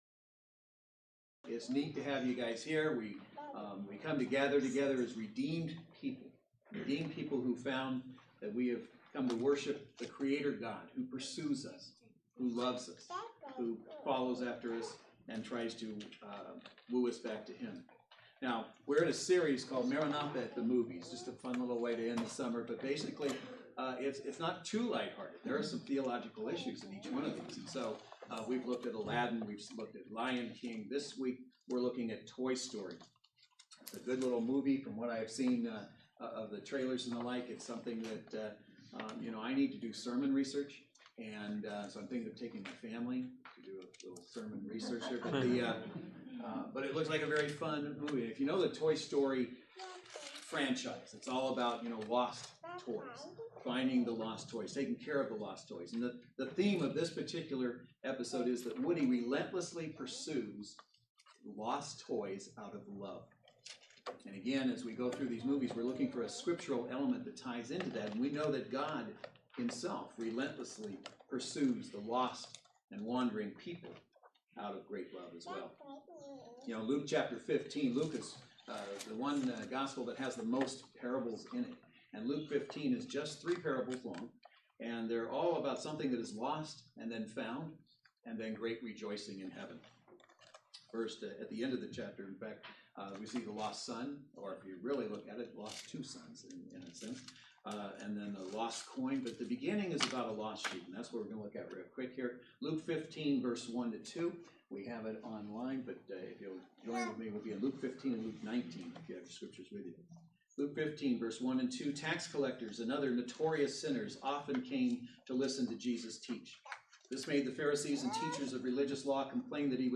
Passage: Luke 15:1-10 Service Type: Saturday Worship Service